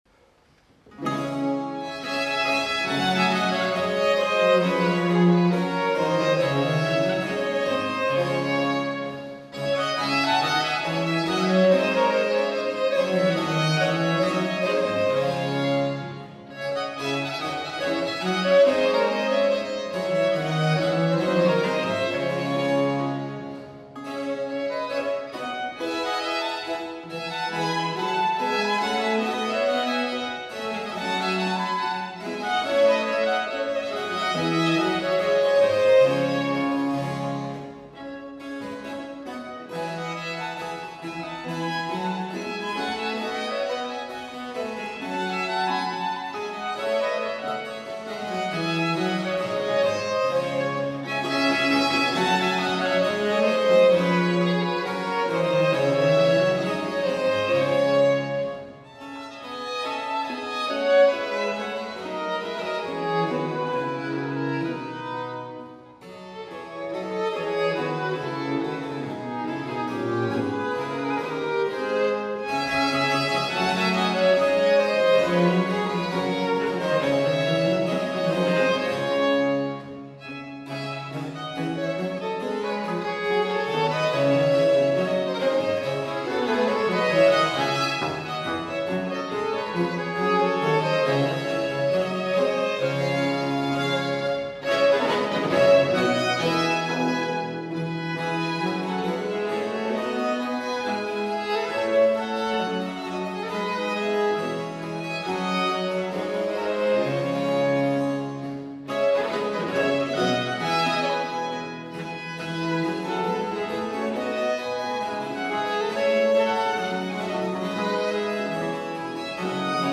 Venue: St. Brendan’s Church
Instrumentation Category:Baroque Ensemble
Instrumentation Other: 2vn, vc, thb, hpd
The memorable opening theme in the upper voices reappears throughout the
piece, interspersed with passages of free variations.